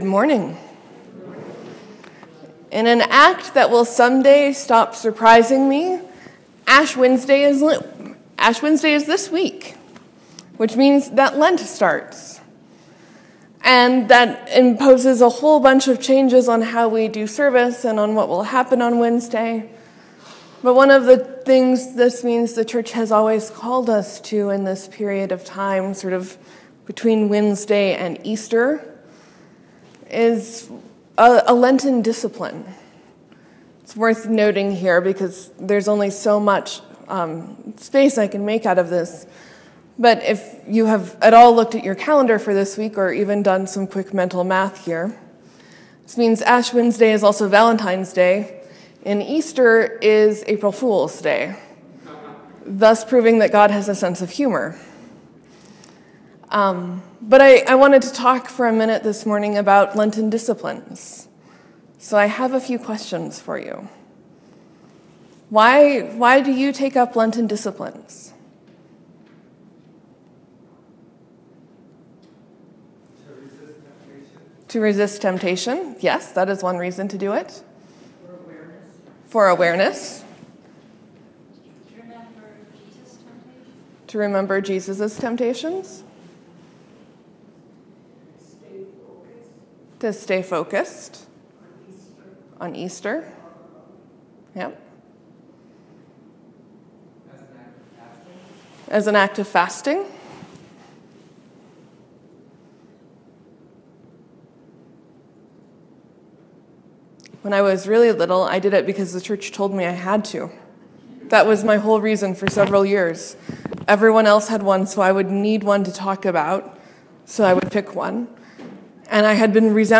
Morsels & Stories: We talked a bit about Lenten practices. This was pretty interactive and I did my best to help the mic get everything.
Sermon: Peter gets it wrong many many times.